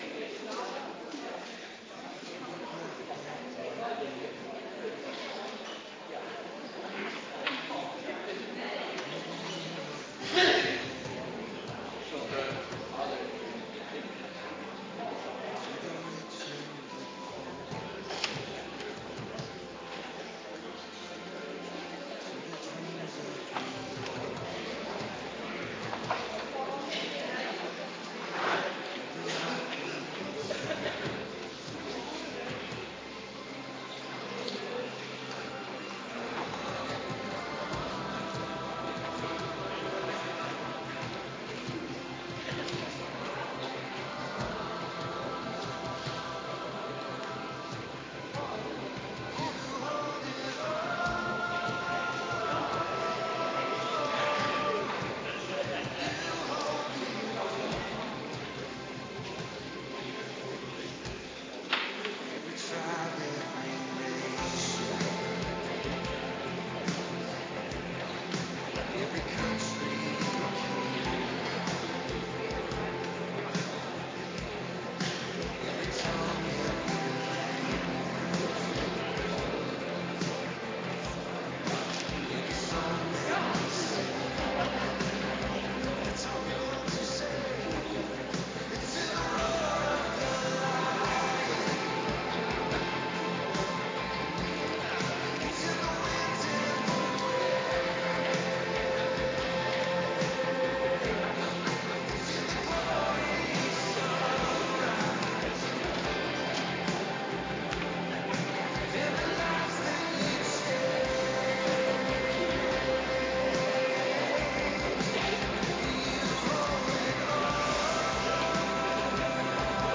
Adventkerk Zondag week 40